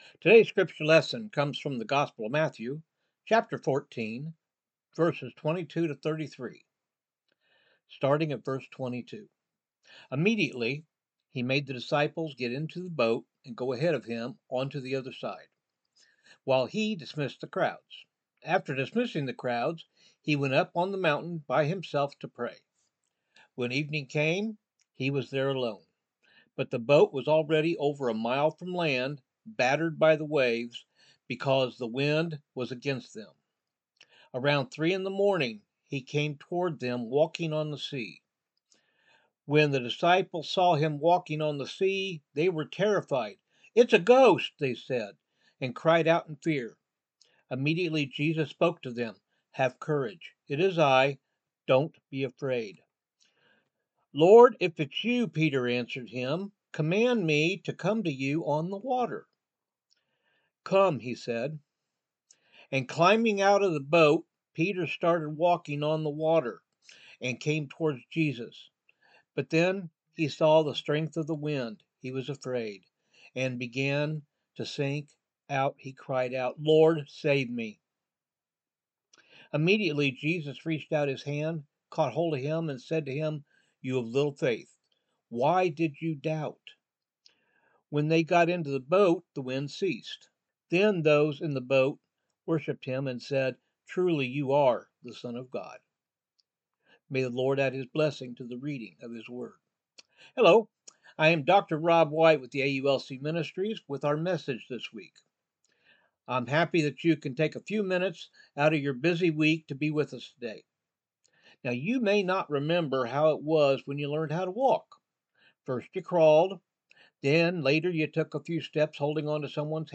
Recent Sermons – AULC Ministries